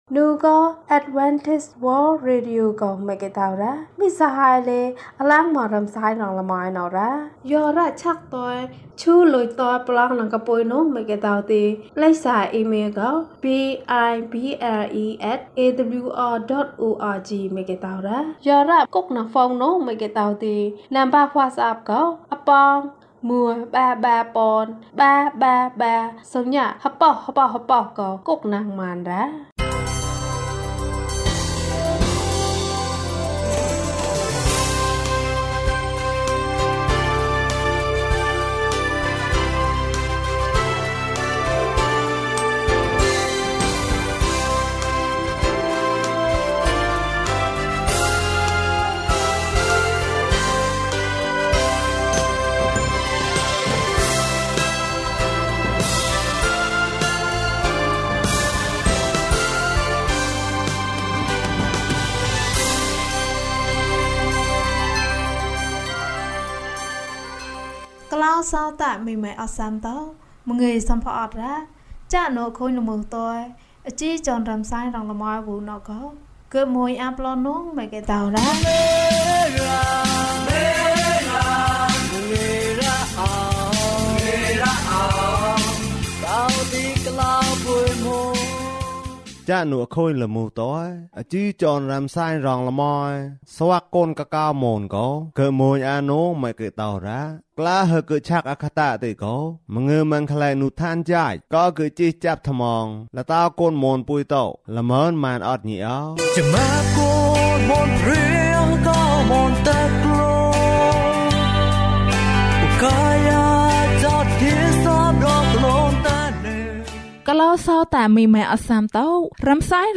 ခရစ်တော်ထံသို့ ခြေလှမ်း။၃၁ ကျန်းမာခြင်းအကြောင်းအရာ။ ဓမ္မသီချင်း။ တရားဒေသနာ။